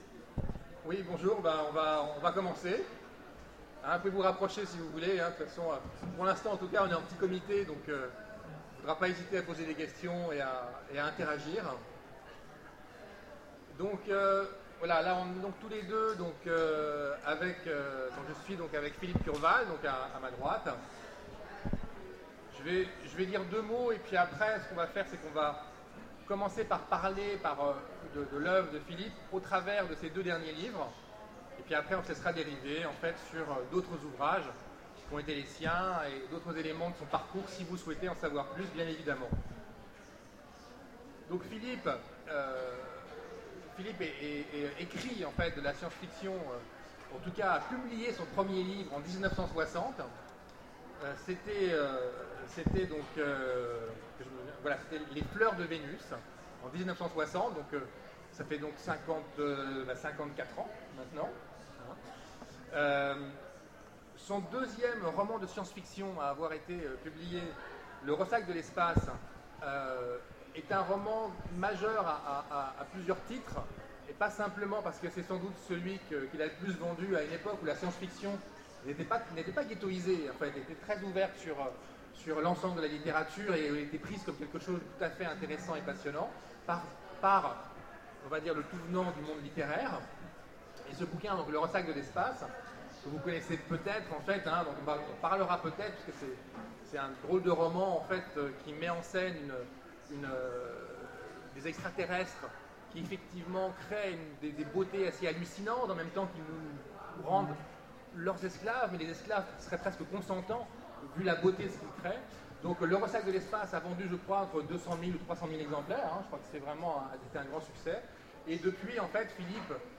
- le 31/10/2017 Partager Commenter Utopiales 2014 : Rencontre avec Philippe Curval Télécharger le MP3 à lire aussi Philippe Curval Genres / Mots-clés Rencontre avec un auteur Conférence Partager cet article